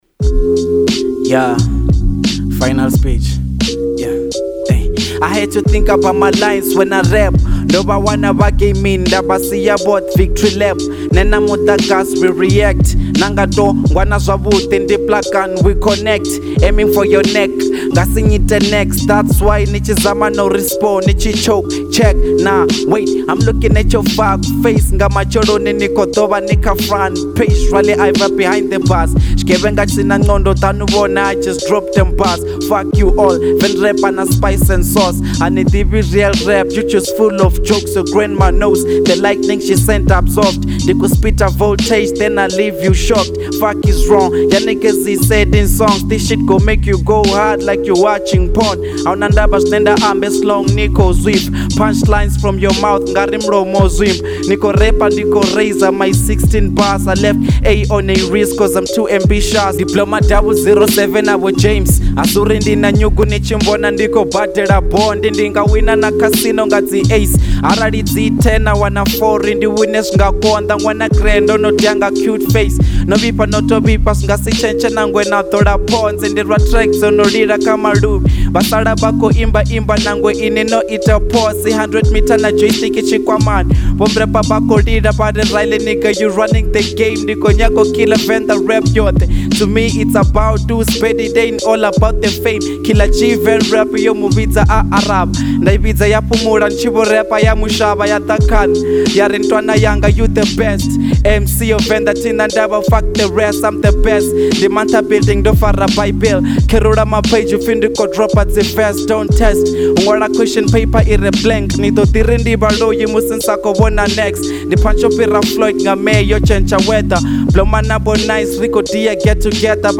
02:27 Genre : Venrap Size